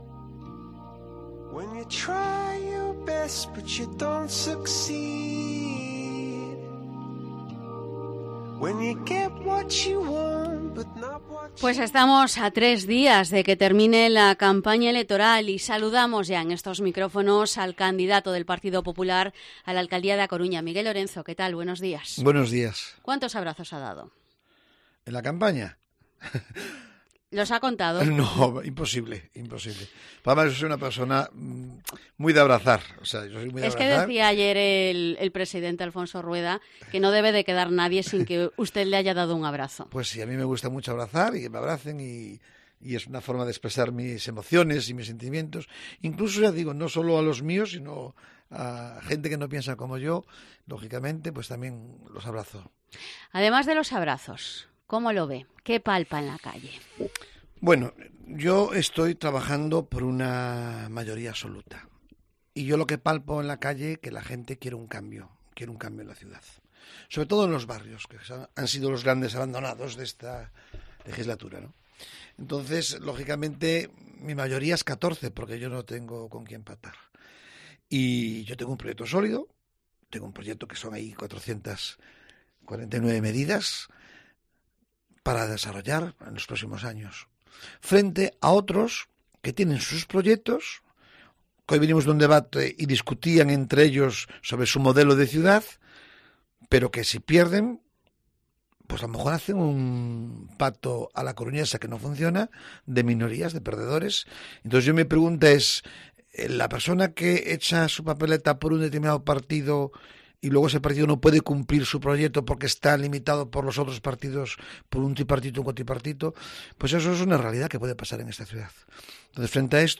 Entrevista a Miguel Lorenzo, candidato del PP a la alcaldía de A Coruña